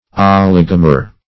oligomer - definition of oligomer - synonyms, pronunciation, spelling from Free Dictionary
oligomer \o*lig"o*mer\, n. (Chem.)